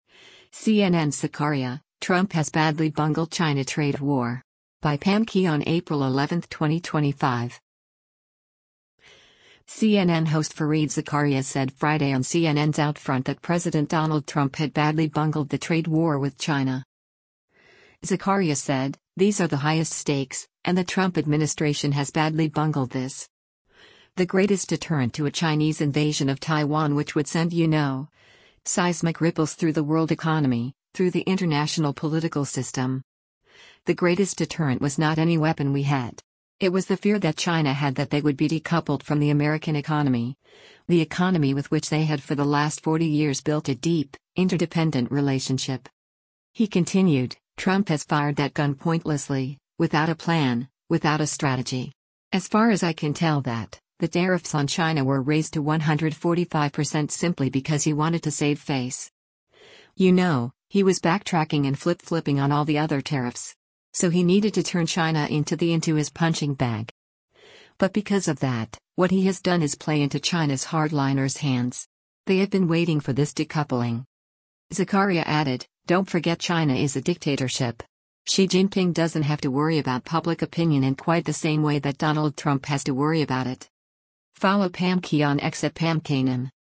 CNN host Fareed Zakaria said Friday on CNN’s “OutFront” that President Donald Trump had “badly bungled” the trade war with China.